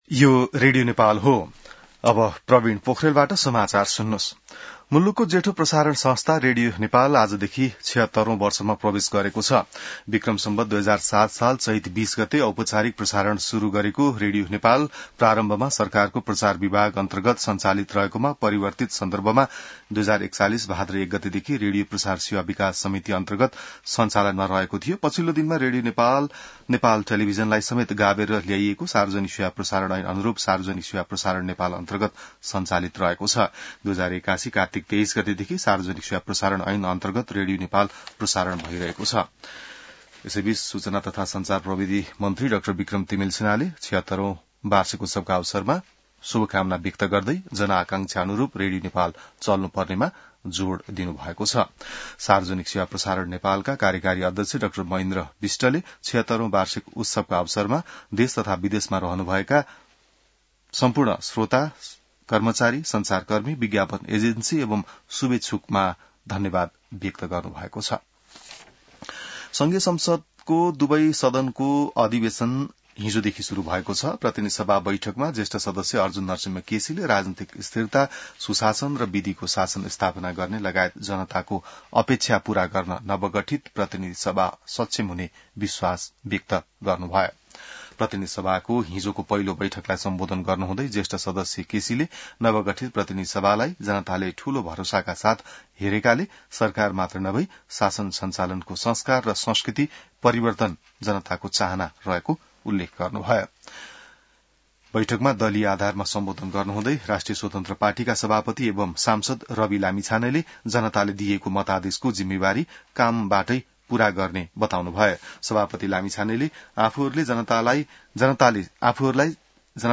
बिहान ६ बजेको नेपाली समाचार : २० चैत , २०८२